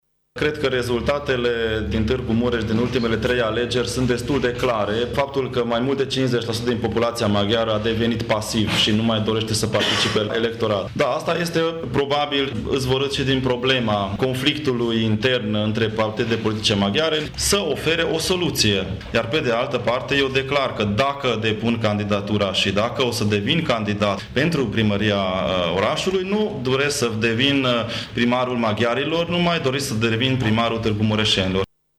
El a spus astăzi, într-o conferință de presă, că va respecta opțiunile cetățenilor maghiari care se vor exprima la prealegerile din interiorul UDMR.